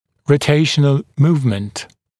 [rəu’teɪʃənl ‘muːvmənt][роу’тейшэнл ‘му:вмэнт]ротационное перемещение